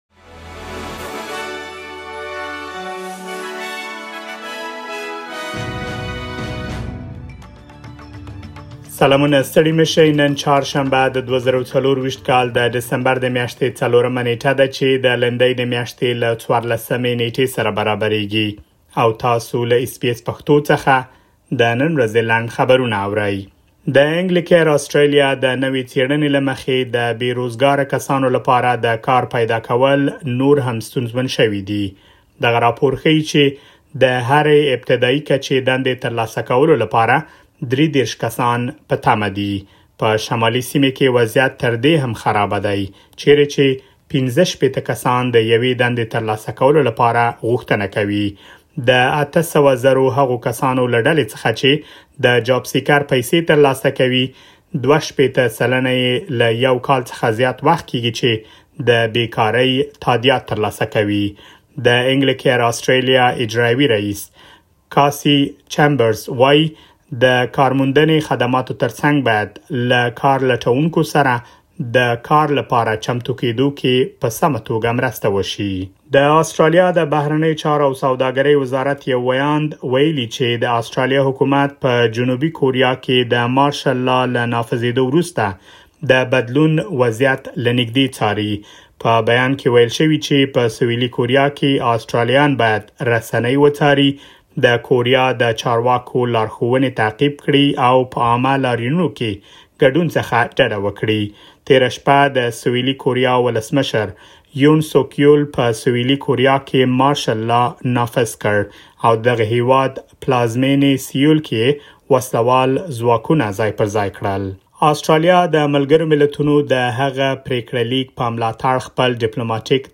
د اس بي اس پښتو د نن ورځې لنډ خبرونه |۴ ډسمبر ۲۰۲۴